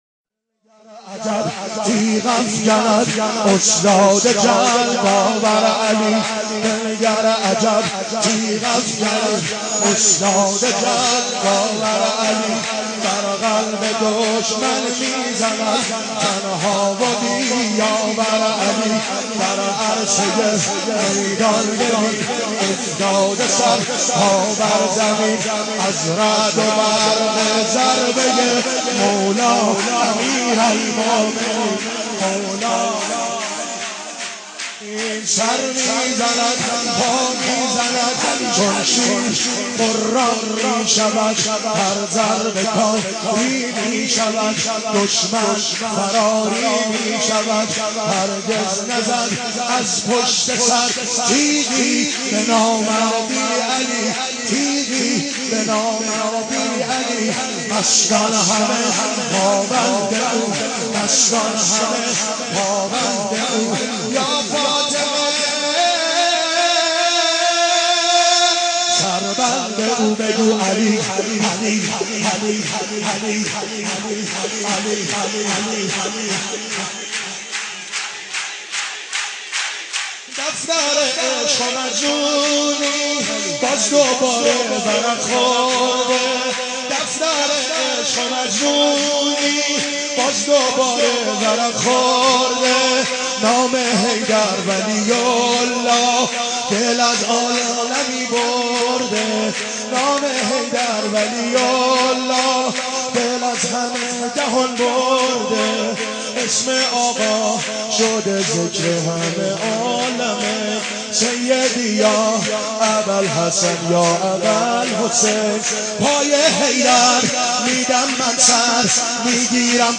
حماسی خوانی